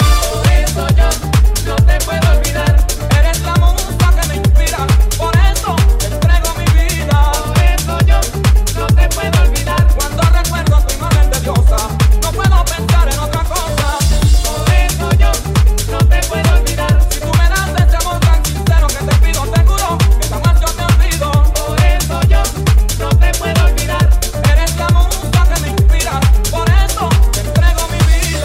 Genere: house,salsa,tribal,afro,tech,remix,hit